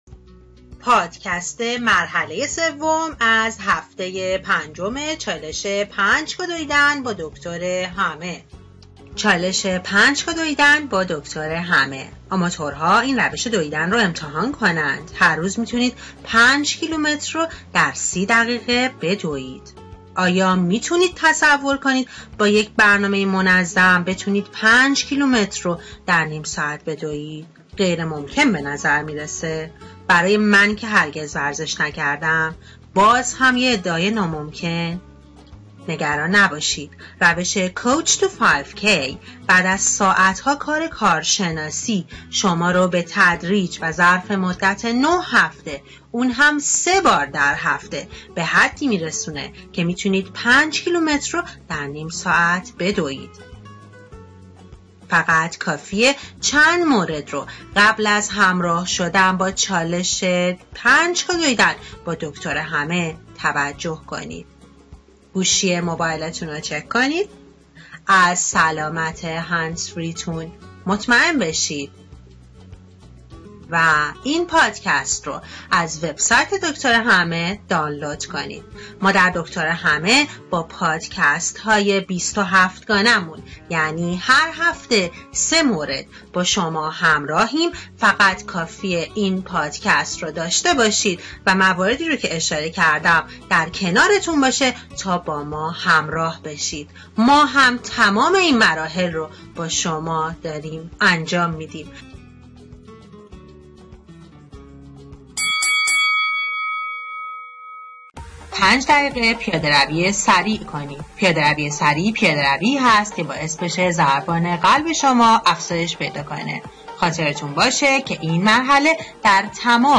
خبر خوب اینست که دکترهمه زمانبندیهای لازم هر مرحله از 27 مرحله چالش 5 کیلومتر دویدن با دکترهمه را در هر پادکست با موسیقی پس زمینه انگیزشی به صورت مجزا برای شما فراهم کرده است.